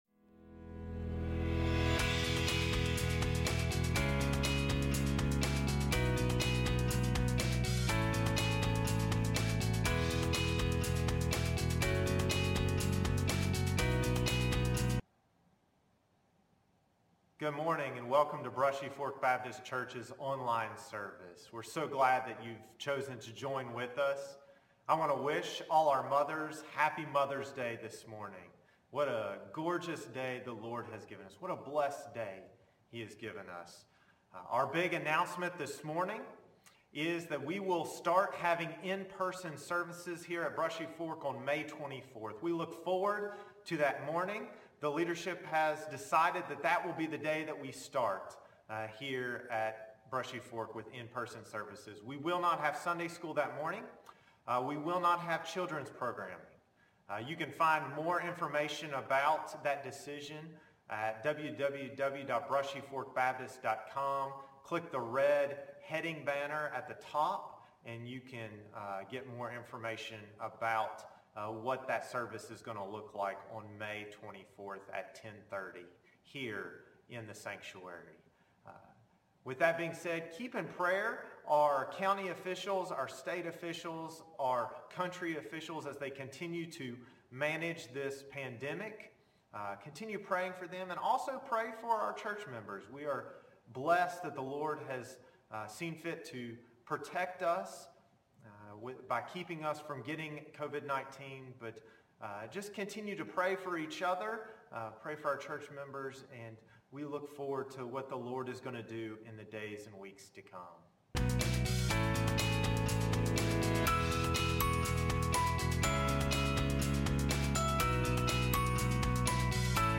Passage: Exodus 2:1-10 Service Type: Sunday Morning Service